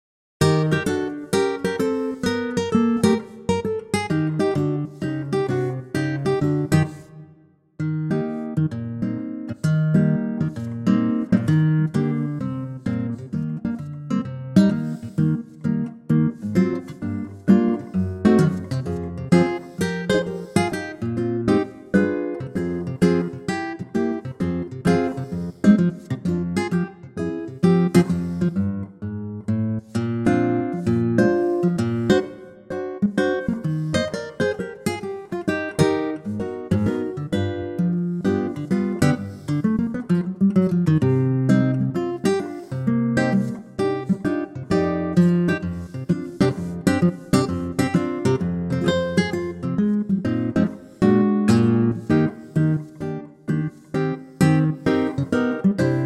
4 bar intro and vocal in at 7 seconds
key - Eb - vocal range - Bb to Eb (optional F)
Wonderful and intimate acoustic guitar arrangement
backing track